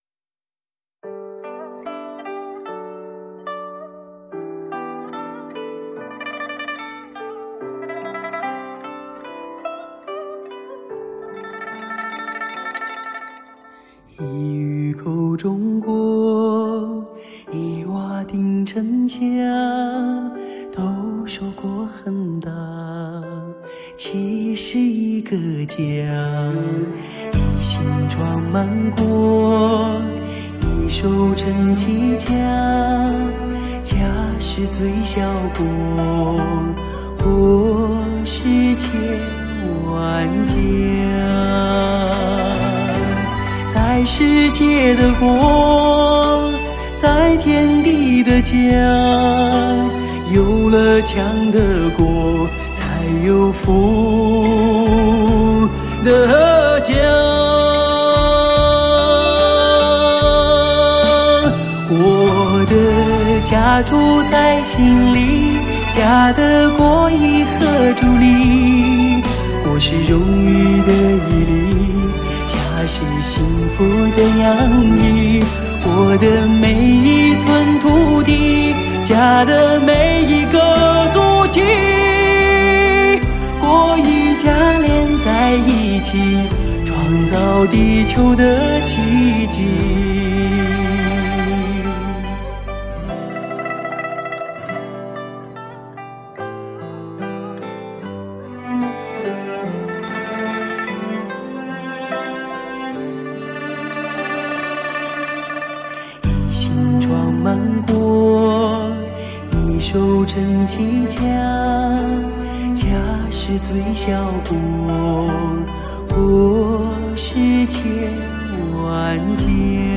开创革命性的 STS+HD 环绕HI-FIAUTO SOUND 专业天碟，
专有STS Magix 母带制作，STS magix virtual live高临场感CD。